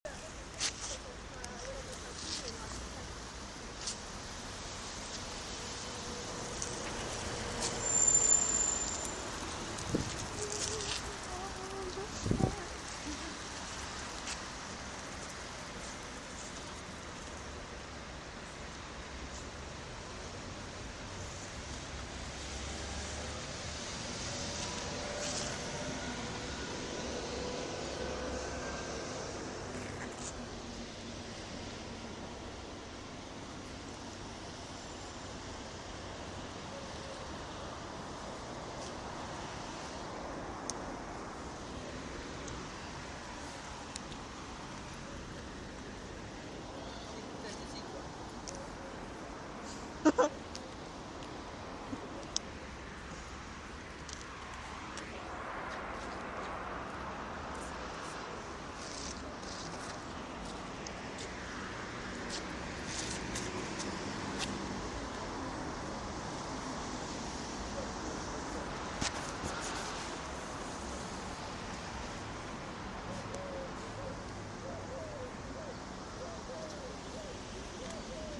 Oiseaux, voitures, bus